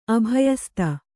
♪ abhayasta